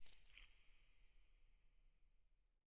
eyeblossom_close2.ogg